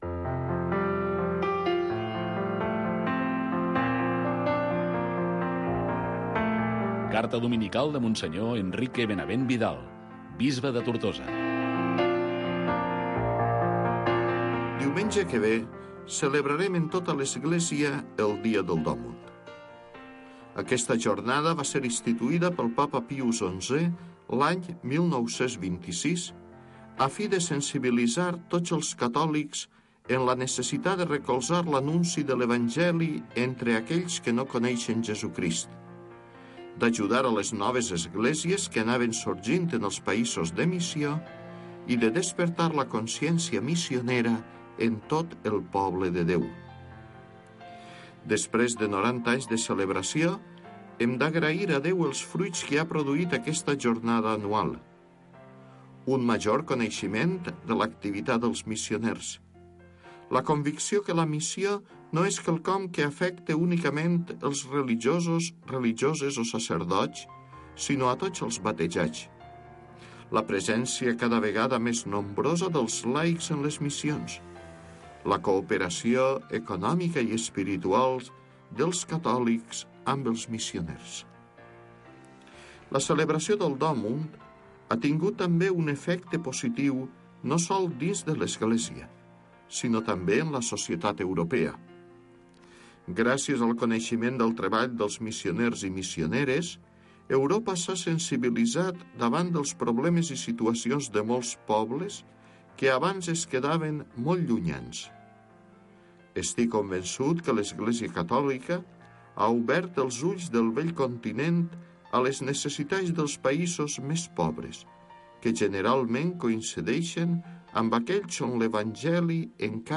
Gènere radiofònic Religió Cultura